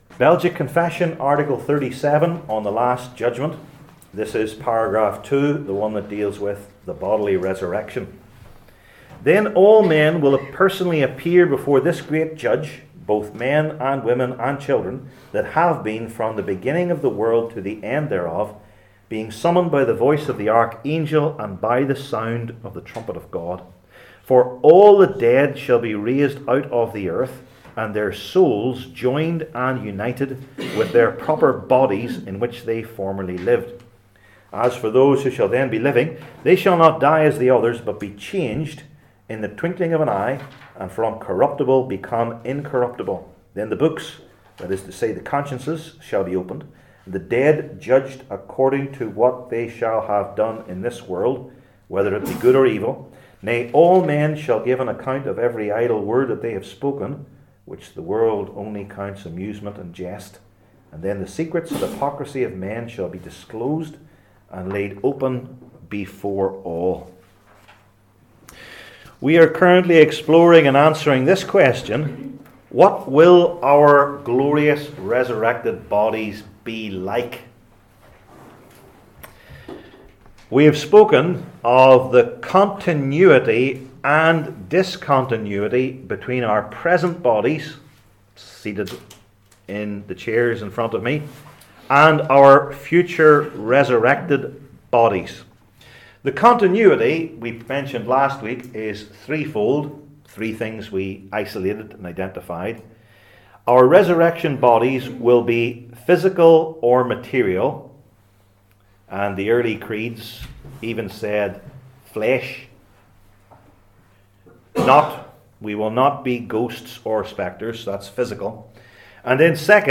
Passage: I Corinthians 15:42-58 Service Type: Belgic Confession Classes